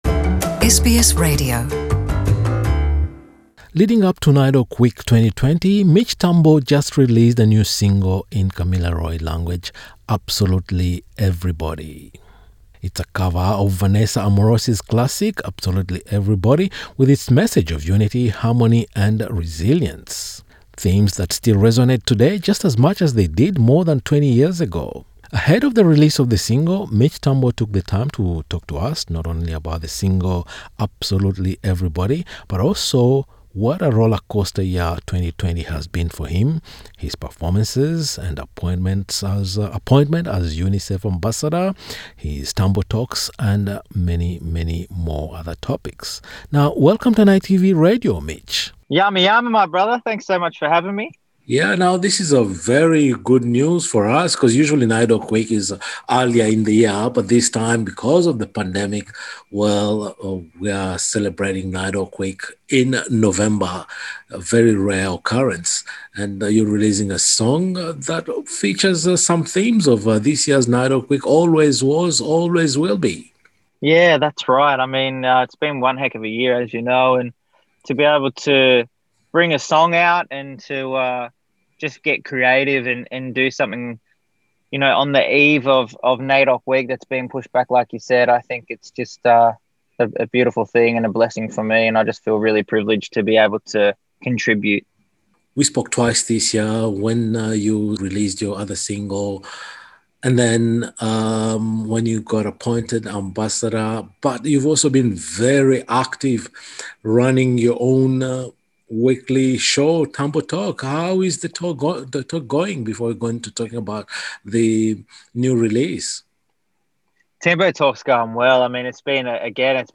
In a conversation with NITV Radio, Mitch Tambo explained that TAMBO TALK has resonated with audiences due to his ability and passion for no holds barred conversations covering everything from mental health to Unity and Love.